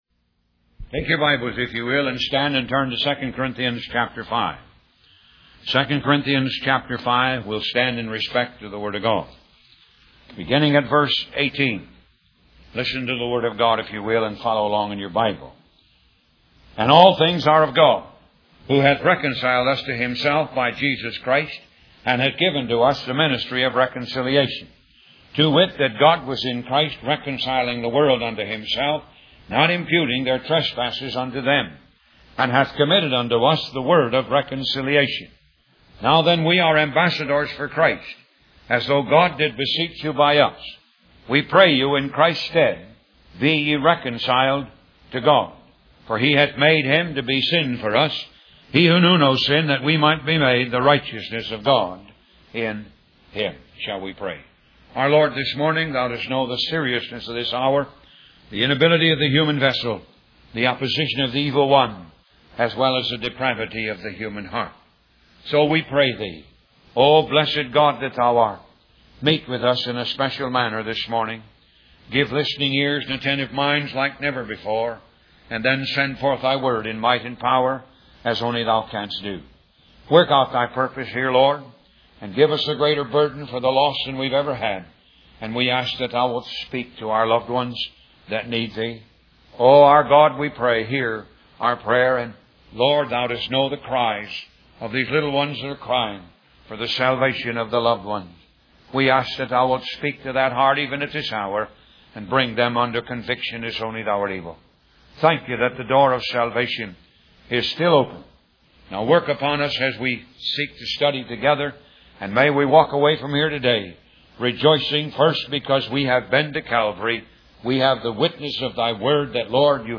Talk Show Episode, Audio Podcast, Moga - Mercies of God Association and The Majesty of Reconciliation on , show guests , about The Majesty of Reconciliation, categorized as Health & Lifestyle,History,Love & Relationships,Philosophy,Psychology,Christianity,Inspirational,Motivational,Society and Culture